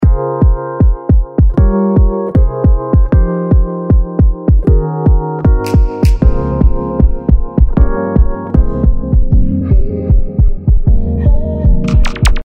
エフェクト単体ですと「HALF TIME」も面白く、曲のスピードを落とすことができるのですが、BANDでかけたい帯域を指定することもでき、様々な使い方ができるかと思います。